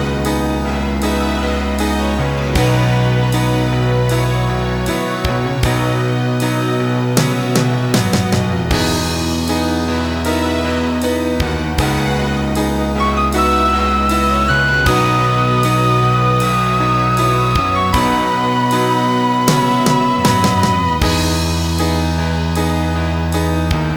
no Backing Vocals Rock 2:59 Buy £1.50